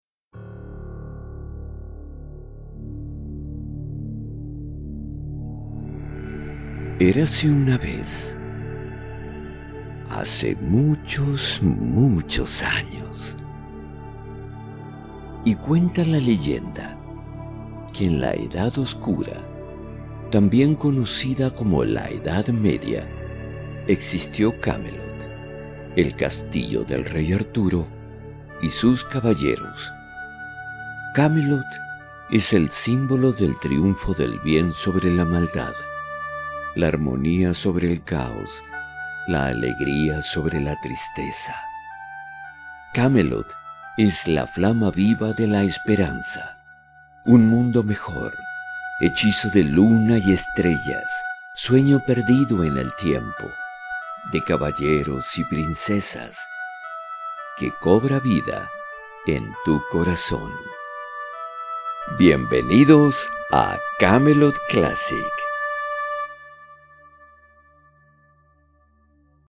Neutral Spanish Voice Over and Dubbing Actor.
Sprechprobe: Sonstiges (Muttersprache):
Wide variety of character voices in spanish and english.